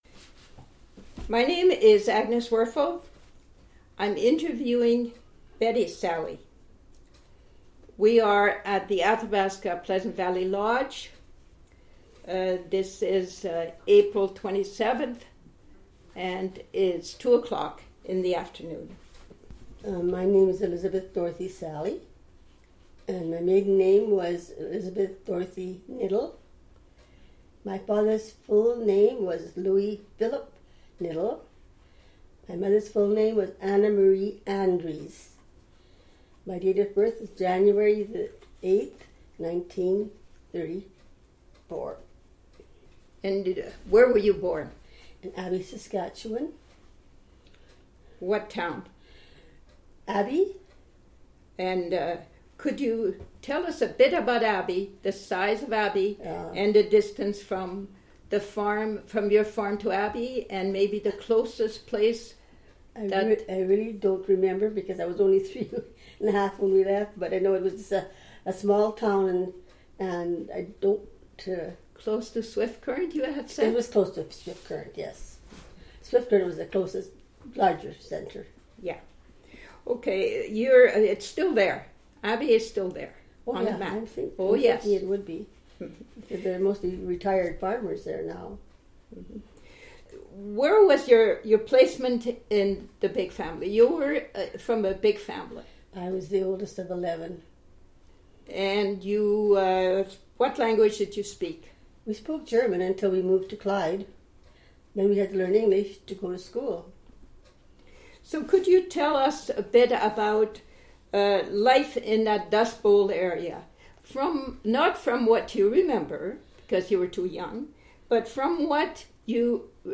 Audio interview,